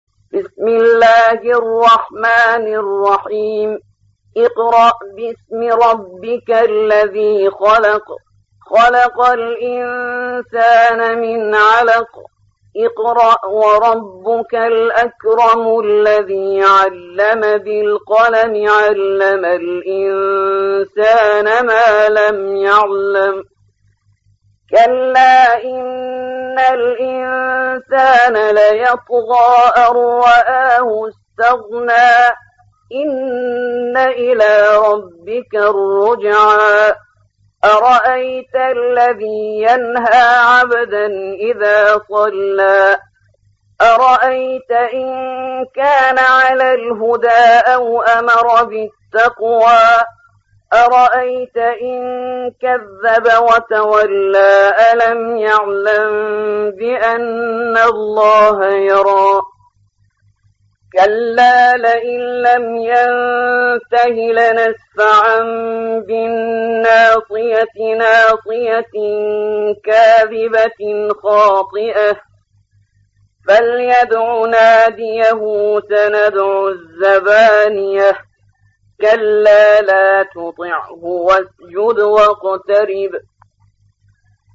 96. سورة العلق / القارئ